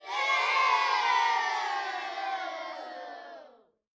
SFX_booing.wav